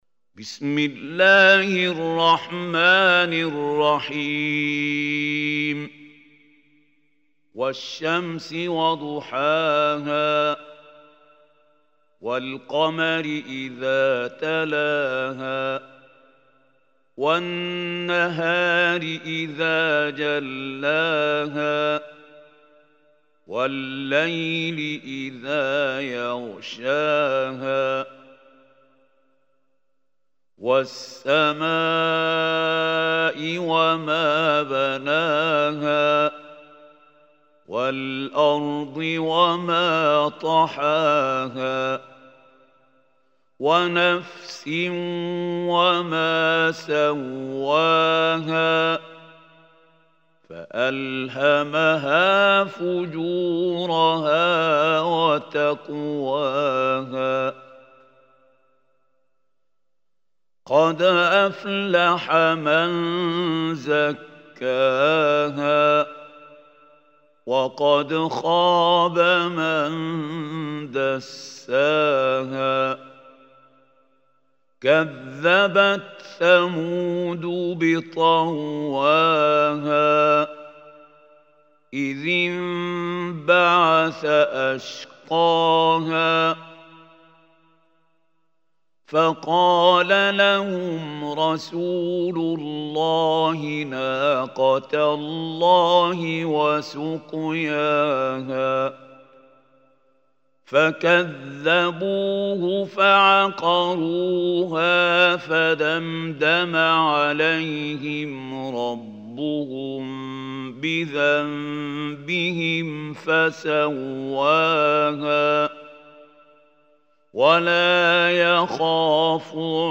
Surah Ash-Shams Recitation by Mahmoud Khalil